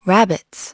/ DictionnaireNathanDKMMMFD.iso / h / hawk / rabbits0.wav ( .mp3 ) < prev Waveform Audio File Format | 1995-04-13 | 8KB | 1 channel | 22,050 sample rate | 0.08 seconds
rabbits0.wav